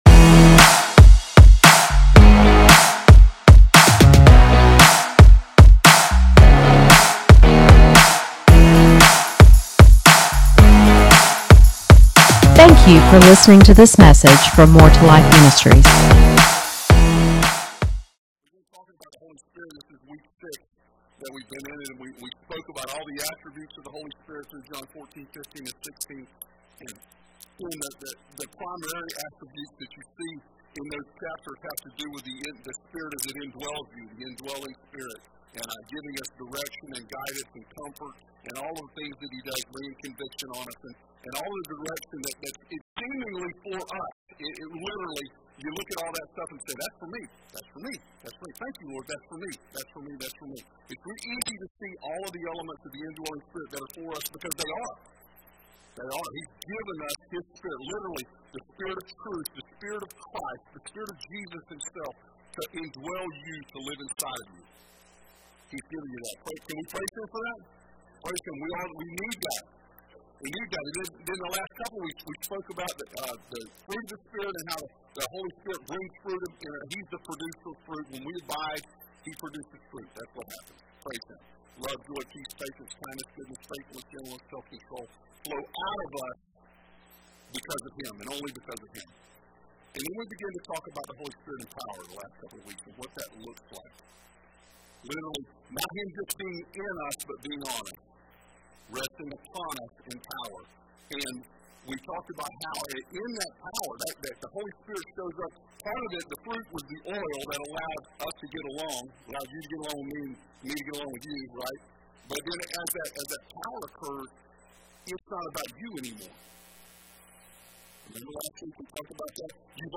Sermons | More 2 Life Ministries